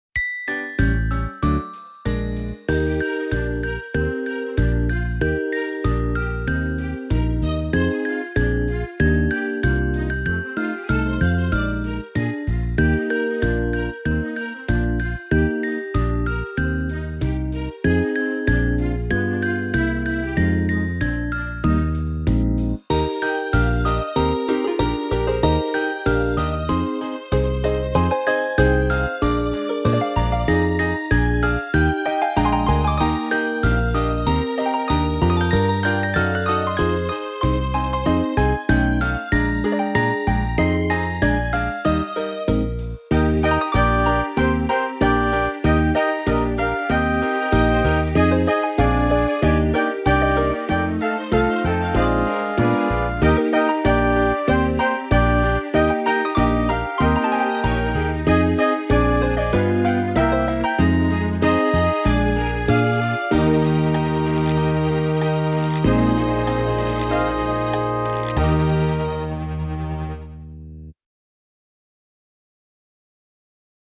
7-Jingle_bells_Karaoke.mp3